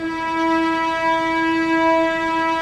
Index of /90_sSampleCDs/Roland LCDP13 String Sections/STR_Vcs II/STR_Vcs6 p Amb